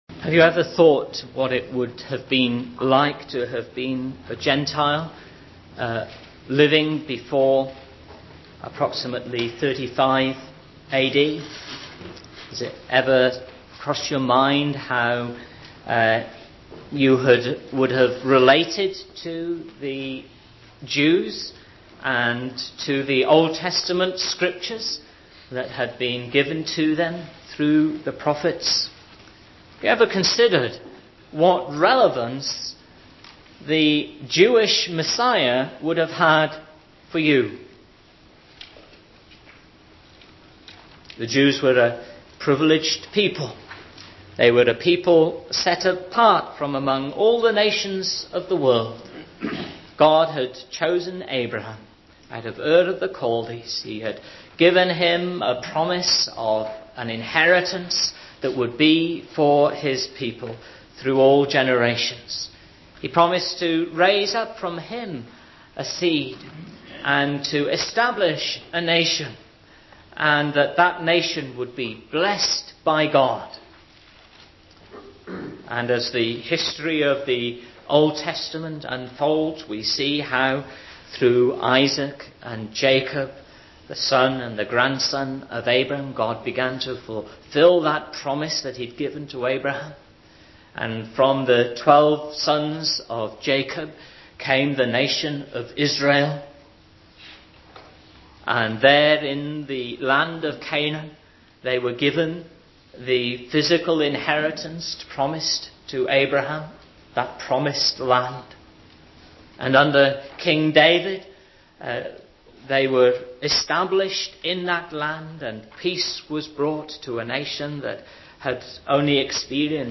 GBC Sermon Archive